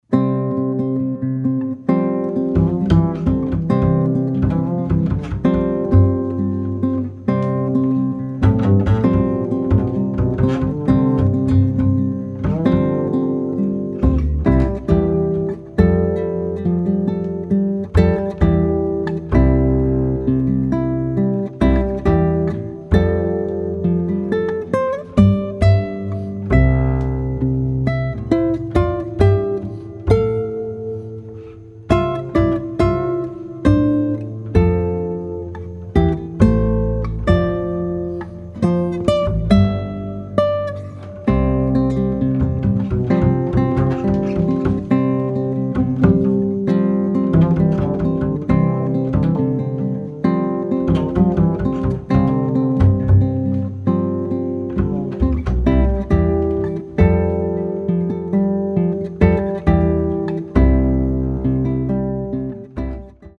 acoustic guitar
acoustic bass